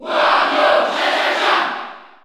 Category: Crowd cheers (SSBU) You cannot overwrite this file.
Wario_Cheer_French_NTSC_SSBU.ogg